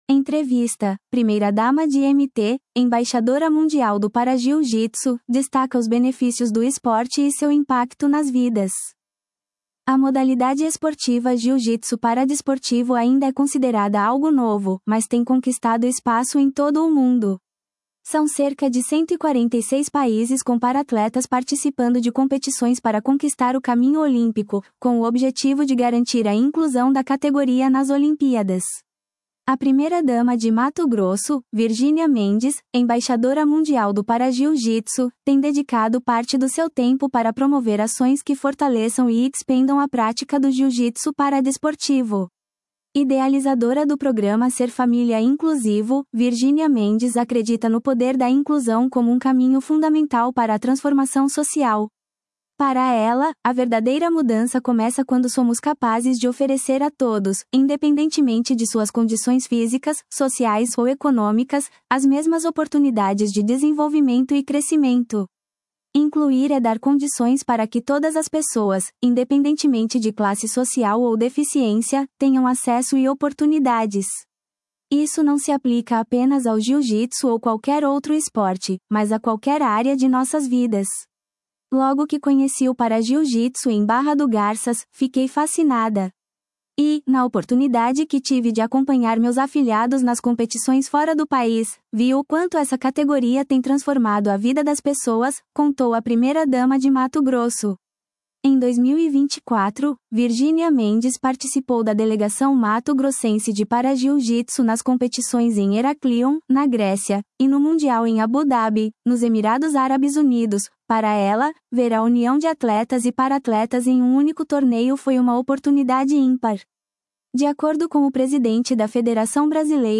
Entrevista: Primeira-dama de MT, Embaixadora Mundial do Parajiu-jitsu, destaca os benefícios do esporte e seu impacto nas vidas